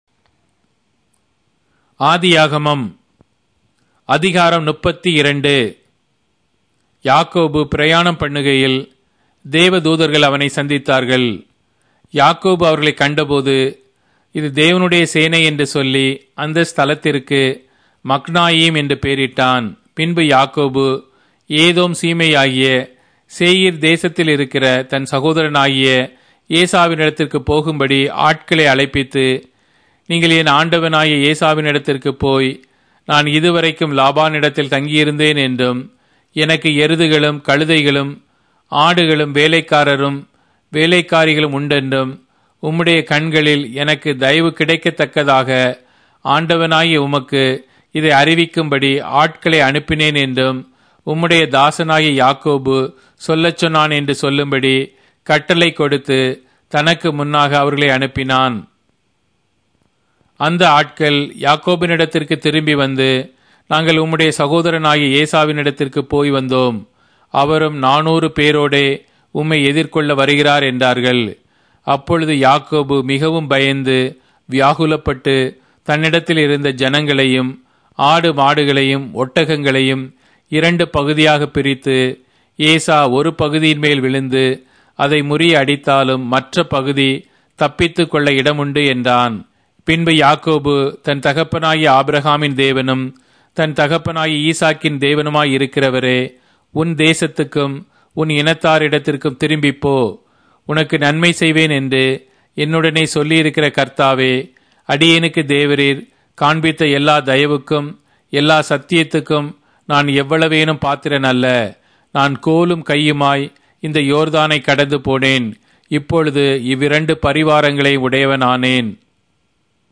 Tamil Audio Bible - Genesis 14 in Kjv bible version